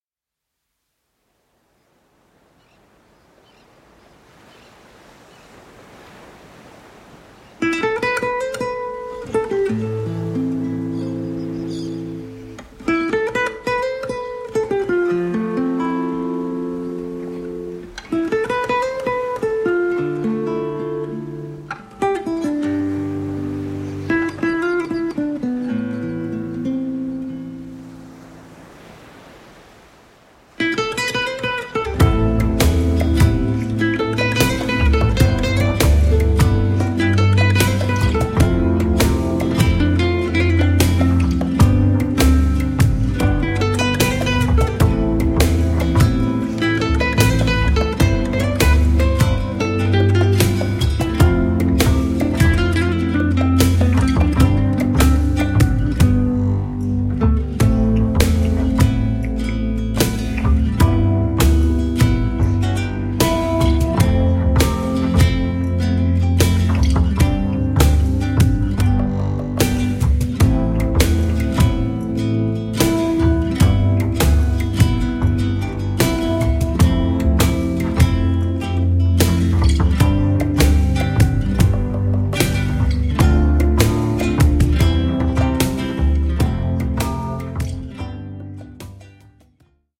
• Stil/Genre: Playback ohne Backings